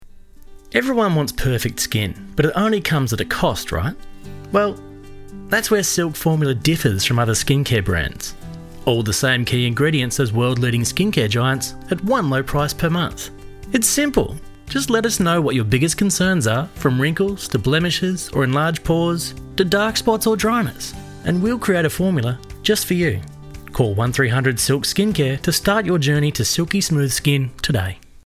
Explainer & Whiteboard Video Voice Overs
Adult (30-50) | Yng Adult (18-29)